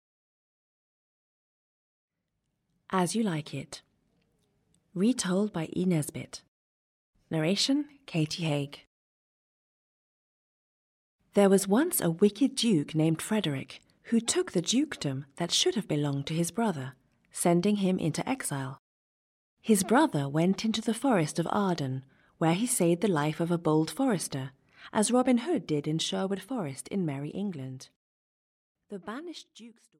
Audio knihaAs You Like It by Shakespeare, a Summary of the Play (EN)
Ukázka z knihy